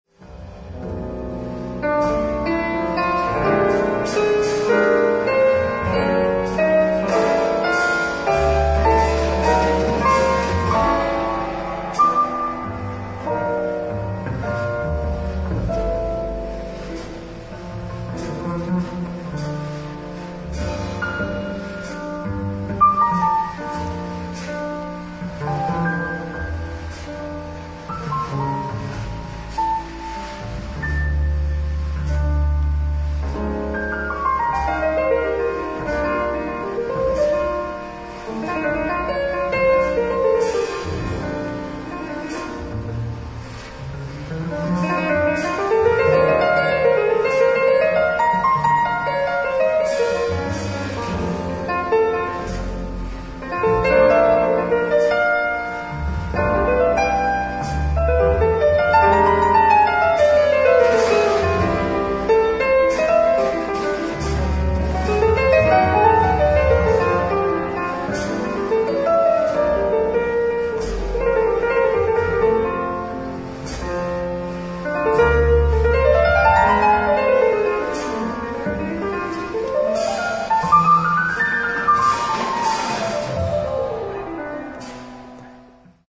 ライブ・アット・フェストシュピールハウス、バーデン・バーデン、ドイツ